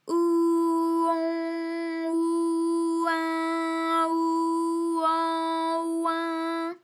ALYS-DB-001-FRA - First, previously private, UTAU French vocal library of ALYS
ou_on_ou_in_ou_an_oin.wav